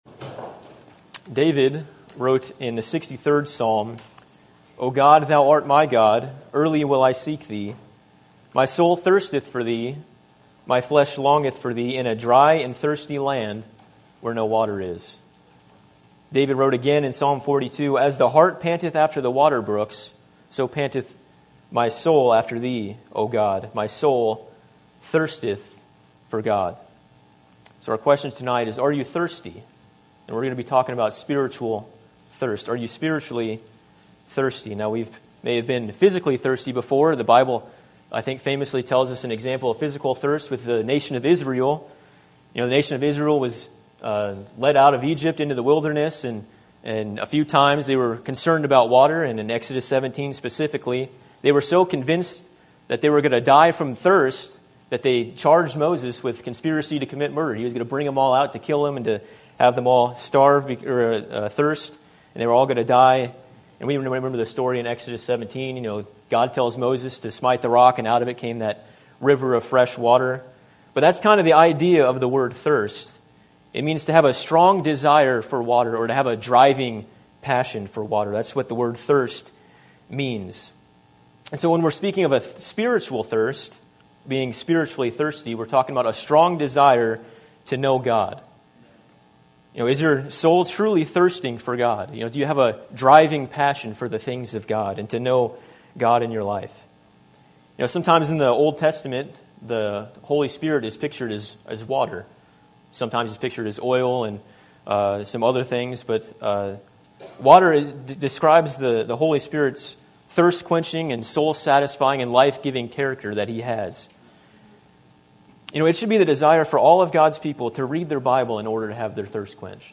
Sermon Topic: General Sermon Type: Service Sermon Audio: Sermon download: Download (15.45 MB) Sermon Tags: Isaiah Dry Water Thirst